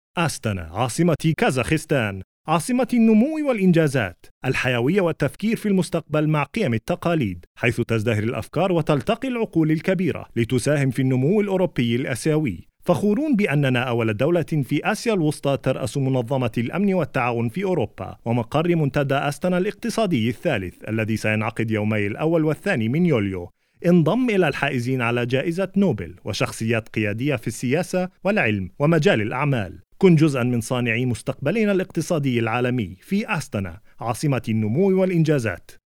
Arabic Voiceovers
kazkhistan-arabic-tv-advert